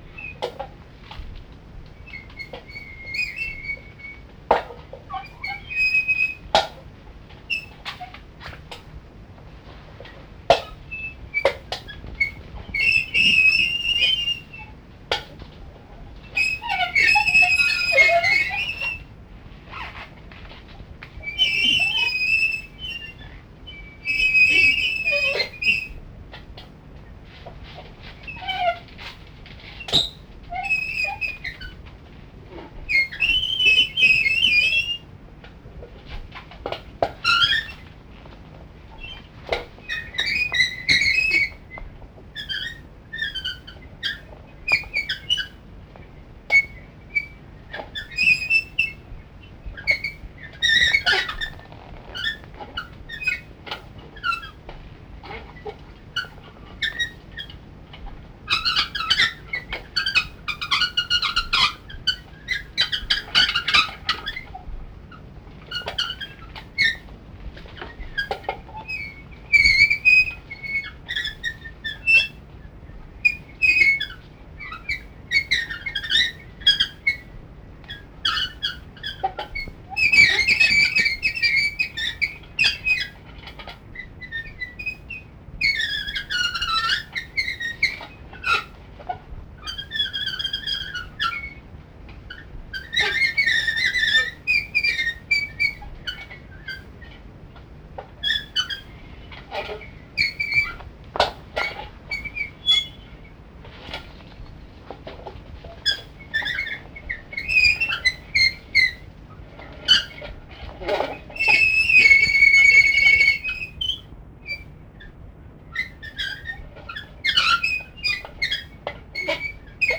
(3)イス･ソロ(20.4MB)